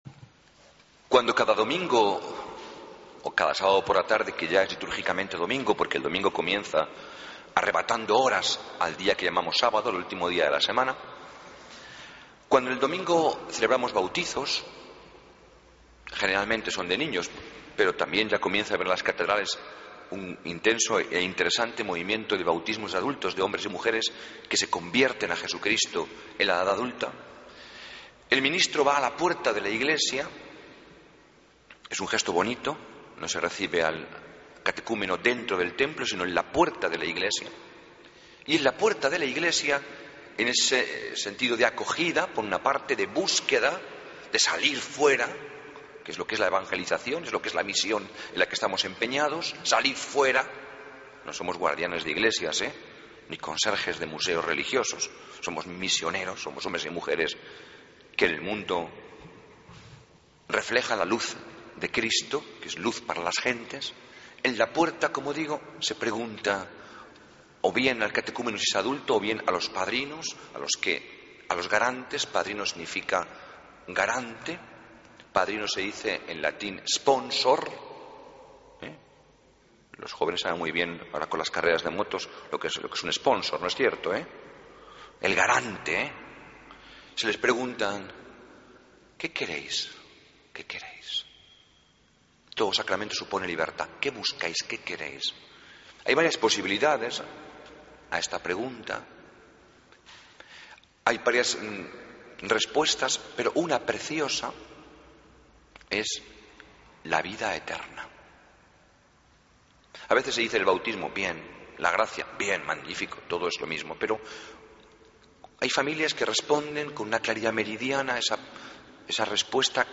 Homilía del 10 de Noviembre de 2013